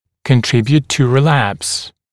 [kən’trɪbjuːt tə rɪ’læps][кэн’трибйут ту ри’лэпс]способствовать возникновению рецидива